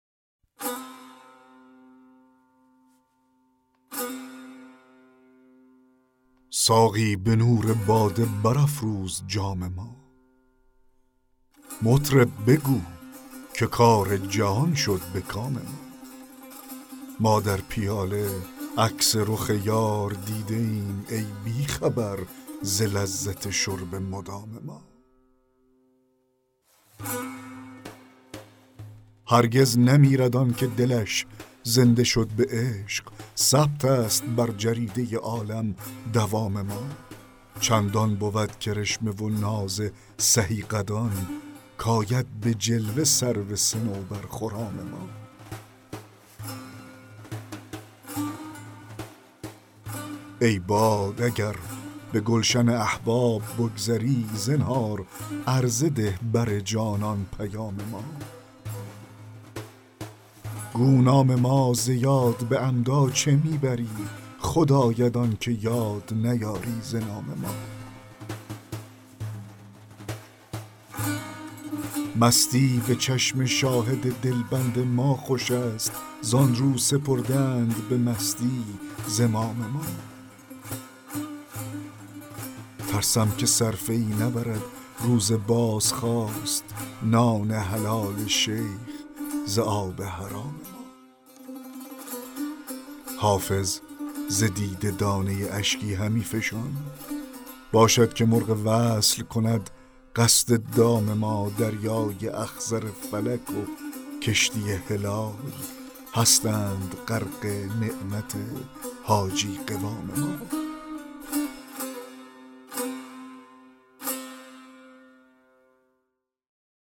دکلمه غزل 11 حافظ